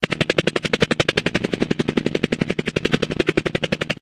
helicopter_rotor.ogg